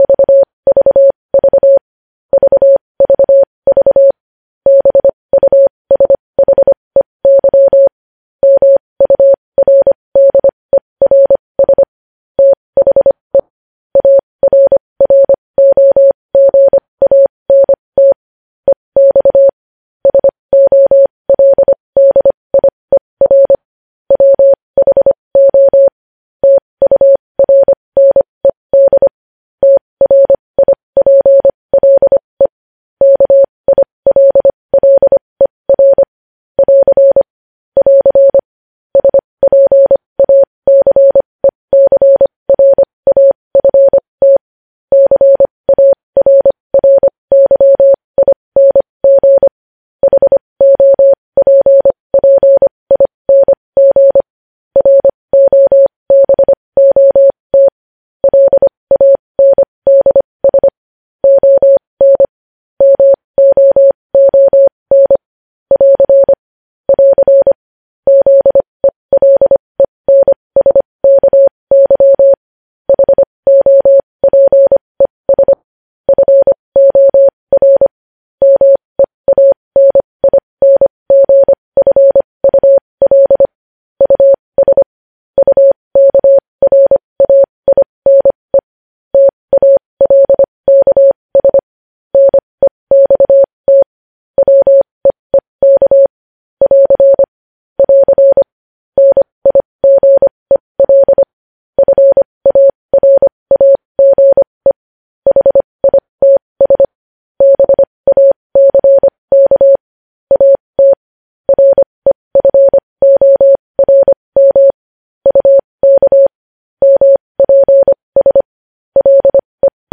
News Headlines at 20 – News Headlines in Morse Code at 20 WPM – Lyssna här